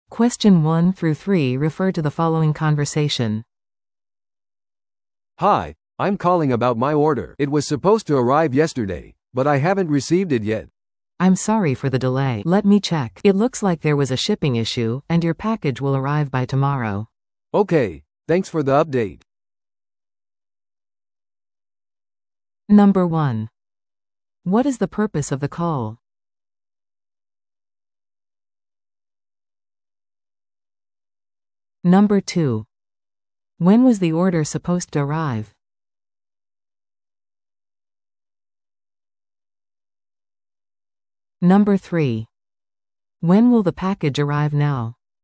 TOEIC PART3の練習問題です。PART3は二人以上の英語会話が流れ、それを聞き取り問題用紙に書かれている設問に回答する形式のリスニング問題です。3人の会話のときもありますので、注意してください。